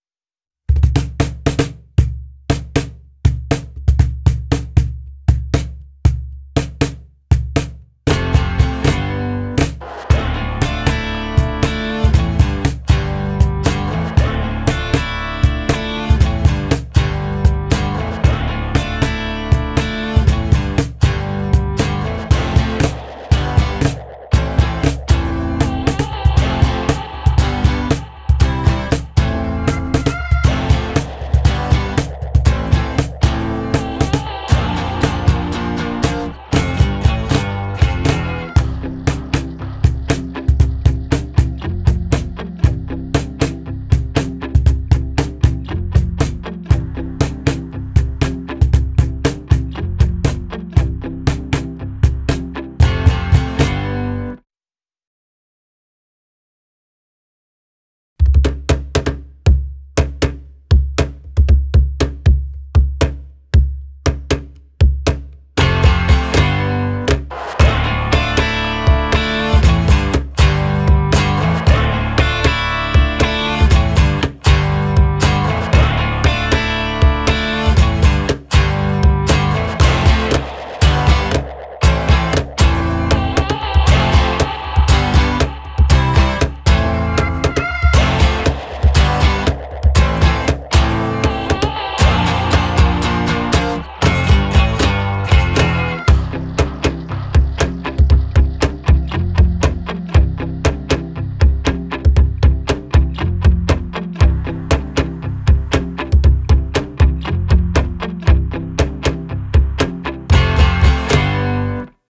MEINL Percussion Snarecraft Professional Series Cajon 100 - Walnut (SCP100WN)
MEINL Percussion Snarecraft Professional Cajons combine articulate rhythmic punctuation with a warm tonal quality to give players a focused sound and…